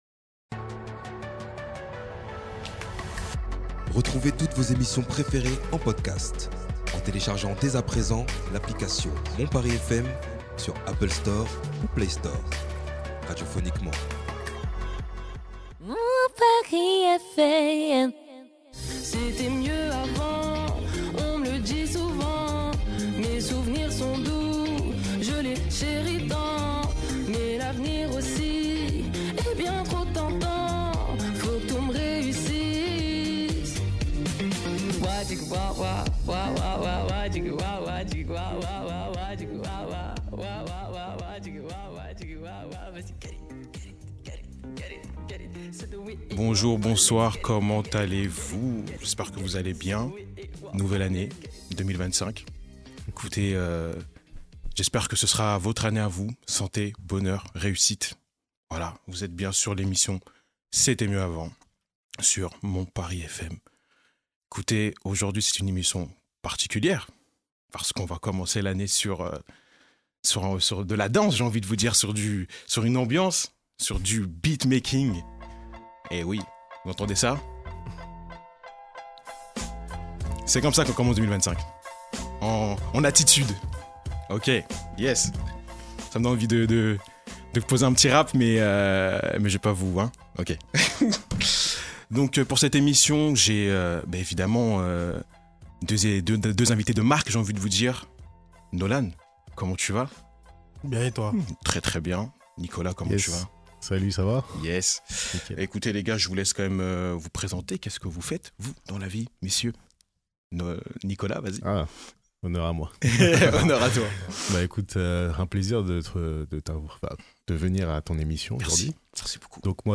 Dans cette émission nous parlerons en profondeur des racines et de l’histoire du beatmaking et également de l’expérience de nos deux invités et bien sûr plusieurs écoute type beat